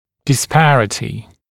[dɪs’pærətɪ][дис’пэрэти]неравенство; несоответствие; несоразмерность